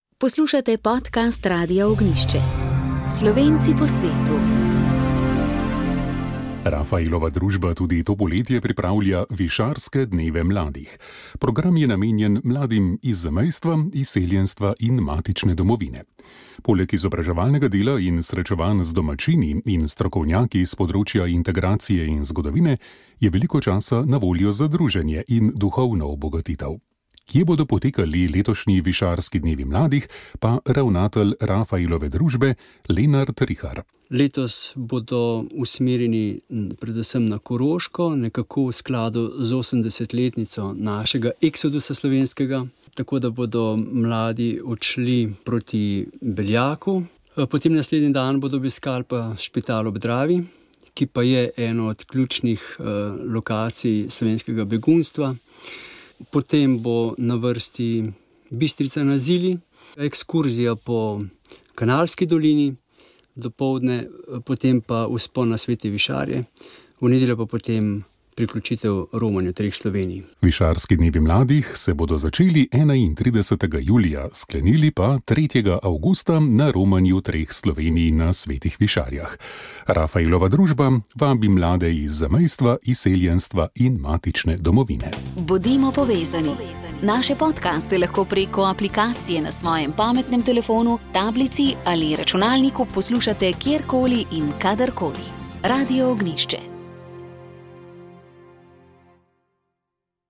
Prenos slovesnosti v spomin na žrtve komunističnega režima
Slovesnost na Trgu republike v Ljubljani se je odvijala v spomin na žrtve komunističnega nasilja, na predvečer ukinjenega narodnega dneva spomina na žrtve komunizma, ki je bil v Sloveniji uveden kot znak spoštovanja do vseh, ki jim je bilo v času komunističnega režima vzeto življenje, dostojanstvo in pravica do spomina.